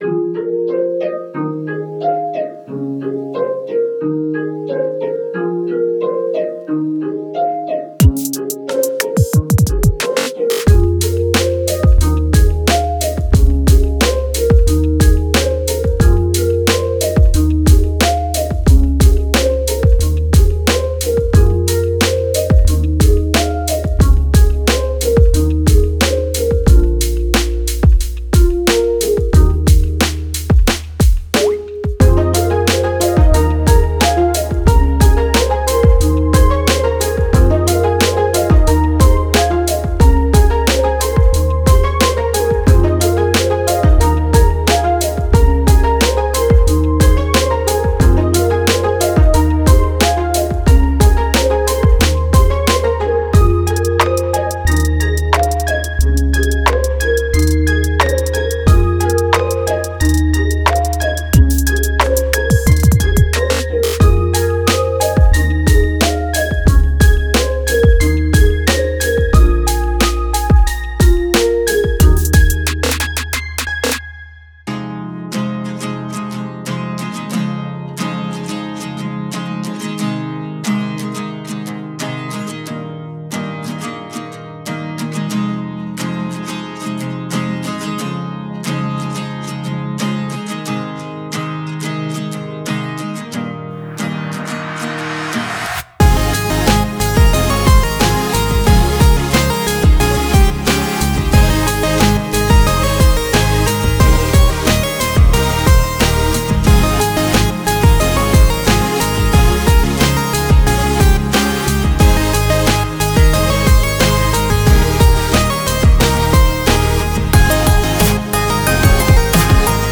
Chillっぽい曲。
■OFF VOCAL